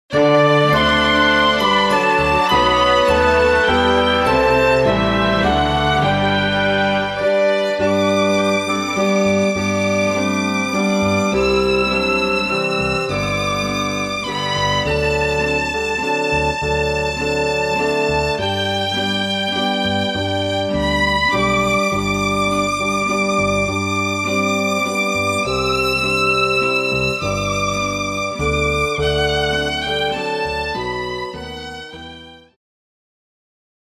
Christmas Karaoke Soundtrack
Backing Track without Vocals for your optimal performance.